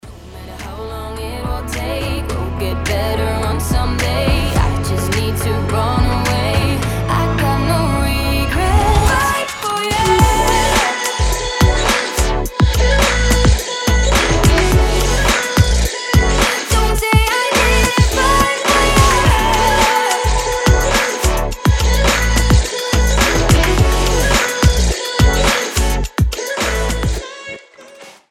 • Качество: 320, Stereo
женский голос
Electronic
нарастающие
тиканье часов
Стиль: future bass